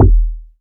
Kicks
KICK.117.NEPT.wav